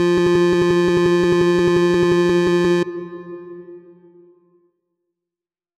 fa.wav